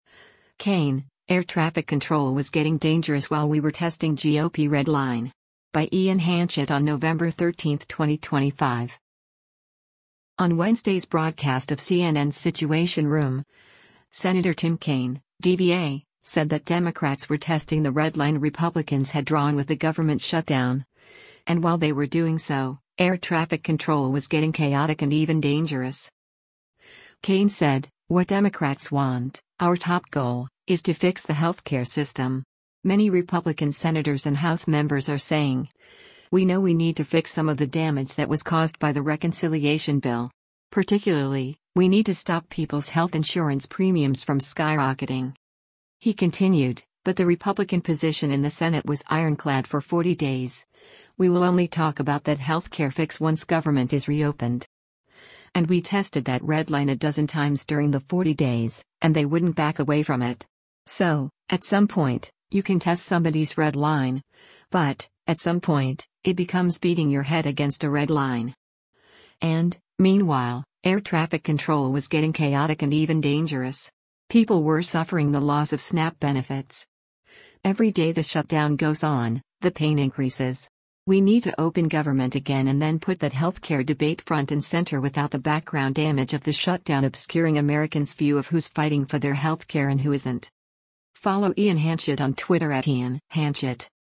On Wednesday’s broadcast of CNN’s “Situation Room,” Sen. Tim Kaine (D-VA) said that Democrats were testing the red line Republicans had drawn with the government shutdown, and while they were doing so, “air traffic control was getting chaotic and even dangerous.”